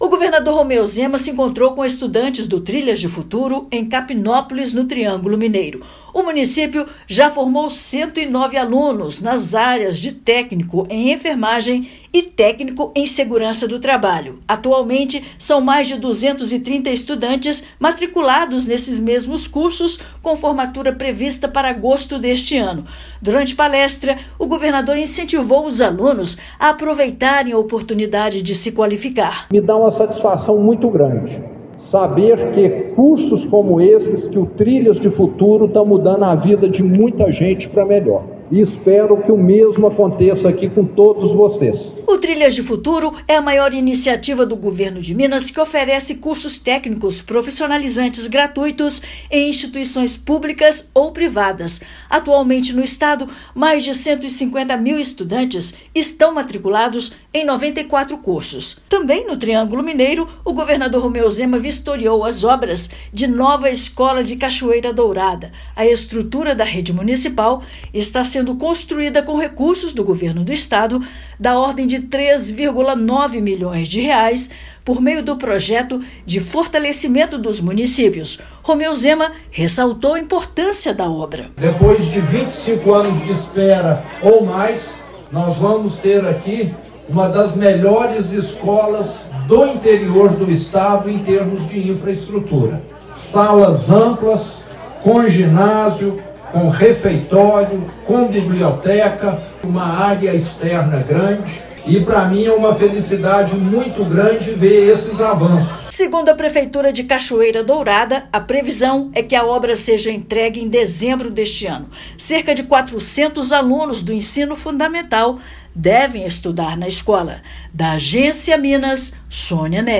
Agência Minas Gerais | [RÁDIO] Trilhas de Futuro contribui para a formação de mais de 230 alunos em Capinópolis
Mais de 200 estudantes do município têm formatura prevista para agosto; em Cachoeira Dourada, governador vistoria obras de nova escola viabilizada com recursos estaduais. Ouça matéria de rádio.